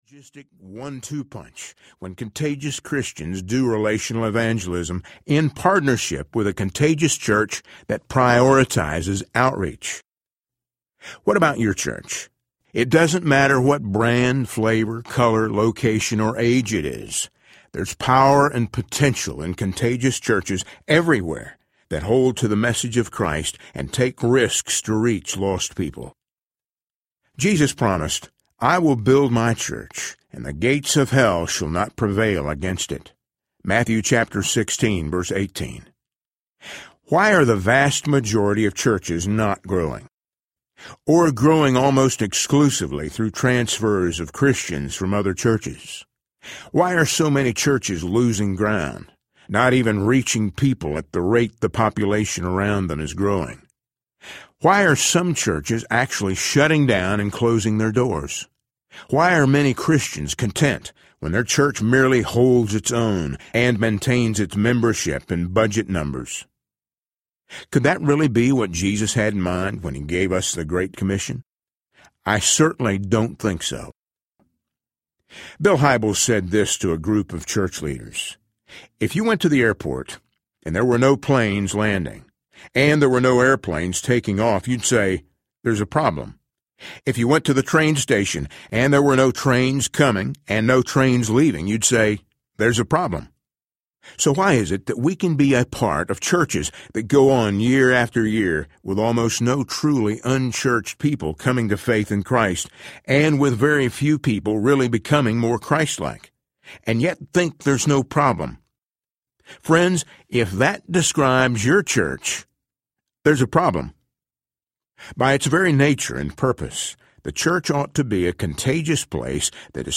Becoming a Contagious Church Audiobook
8.15 Hrs. – Unabridged